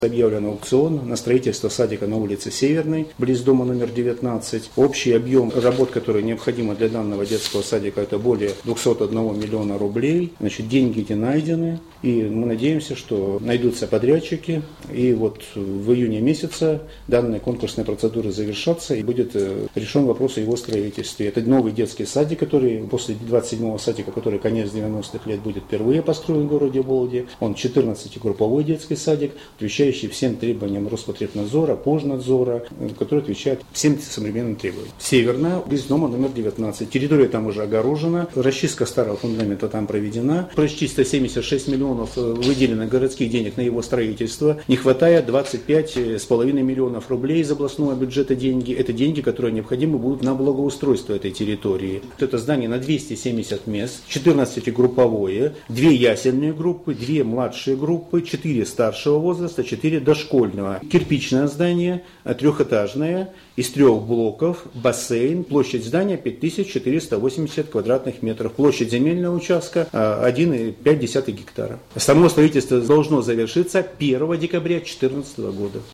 Дошкольное учреждение возведут на улице Северной, 19. Об этом 29 апреля заявил начальник Управления образования города Вологды Николай Колыгин.
Как рассказал корреспонденту ИА «СеверИнформ» Николай Колыгин, детский сад в областной столице построят впервые с конца 90-х годов.